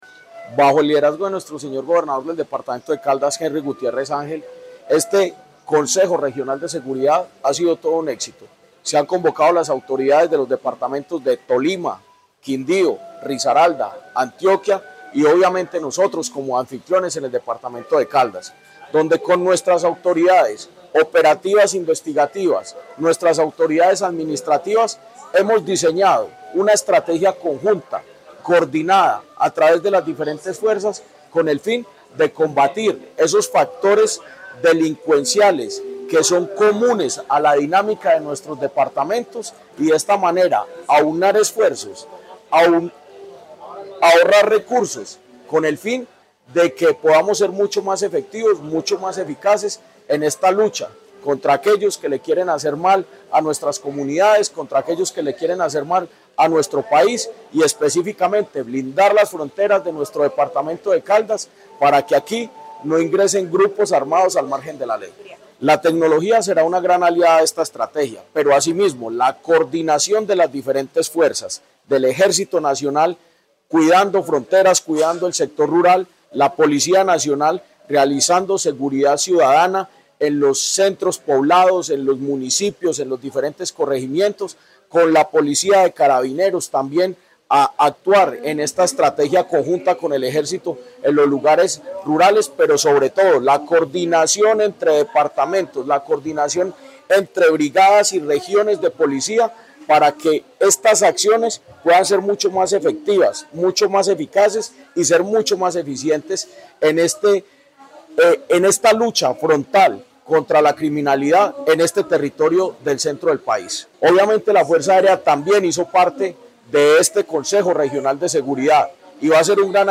Jorge Andrés Gómez Escudero, Secretario de Gobierno de Caldas.